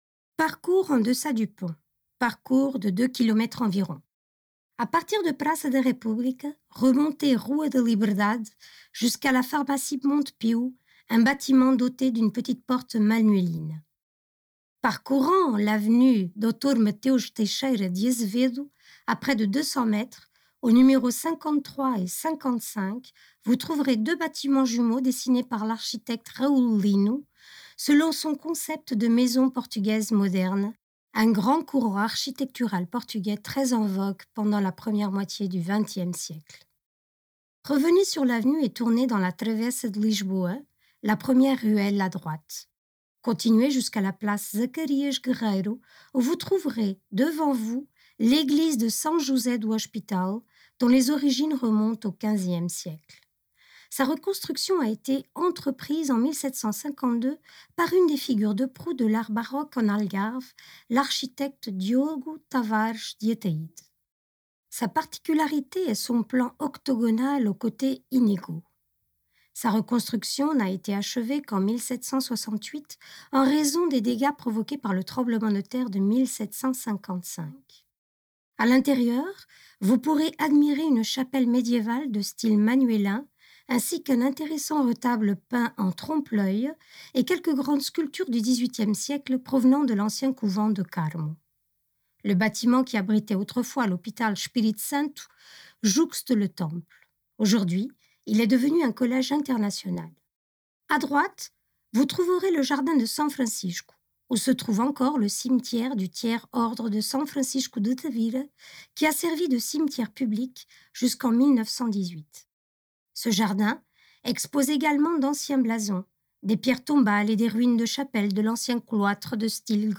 Distance 1.8km Durée moyenne 50 minutes Principaux points à visiter Praça da República; Ancien monastère Nossa Senhora da Piedade (ou Bernardas); Marché Municipal Audio_Guide_En-deçà du pont En-deçà du pont